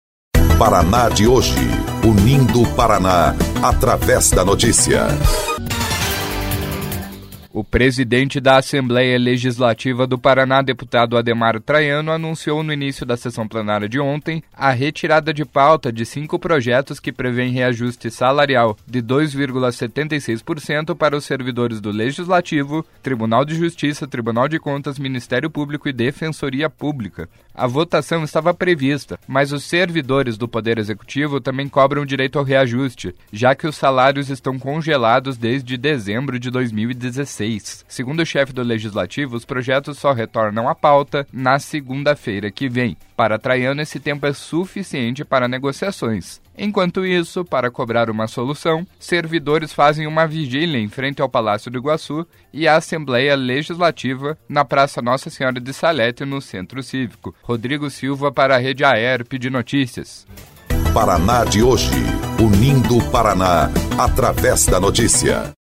12.06 – BOLETIM – Após pressão de servidores, Assembleia adia votação do reajuste de outros poderes